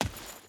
Footsteps / Dirt / Dirt Chain Run 5.wav
Dirt Chain Run 5.wav